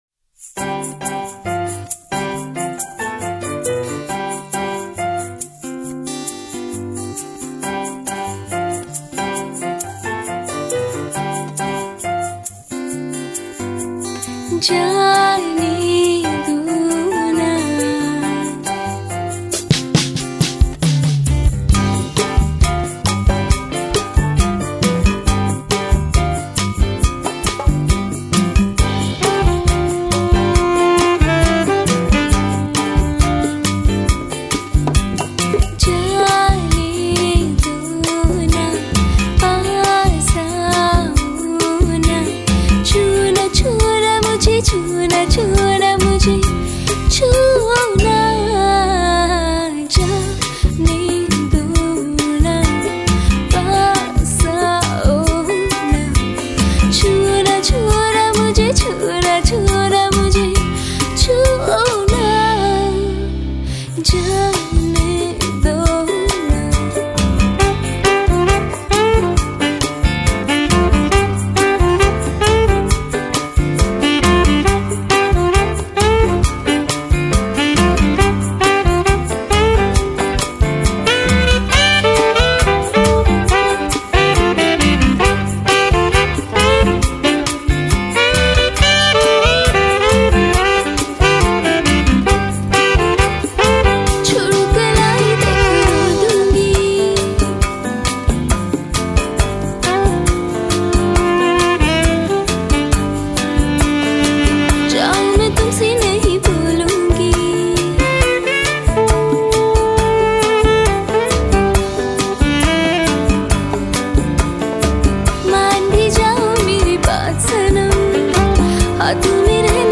Latest Pop Songs